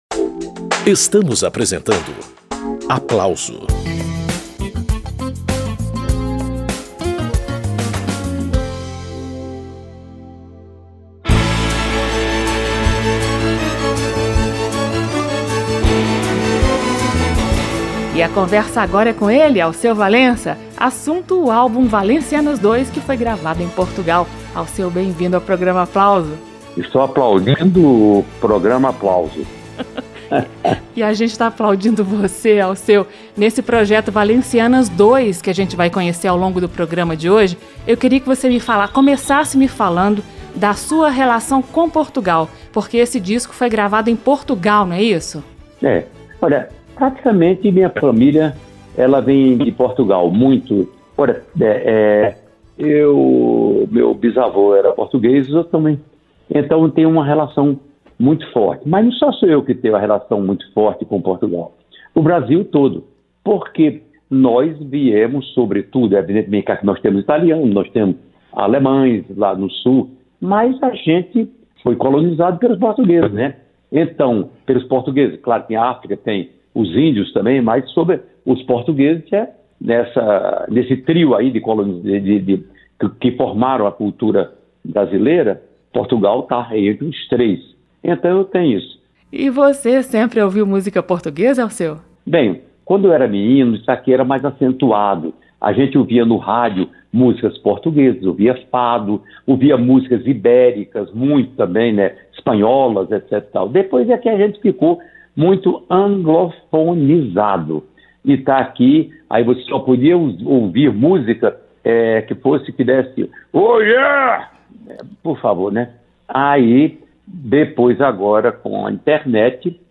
A calorosa recepção da plateia portuguesa
A beleza e o vigor dos arranjos camerísticos